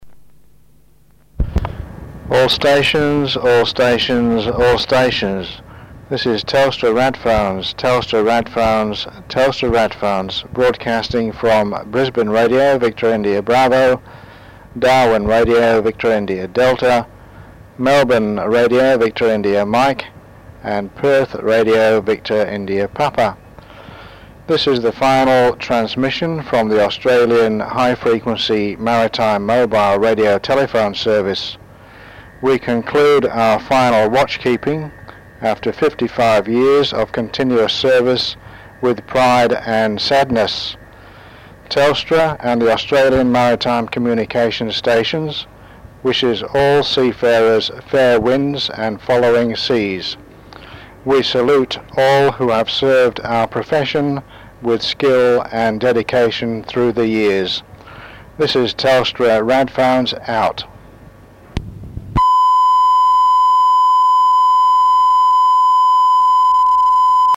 On 28th February 2002 at 2359UTC The Australian HF Maritime Mobile Radio Telephone Service sent its final message:
The transmitters were then cermoniously powered down for the last time.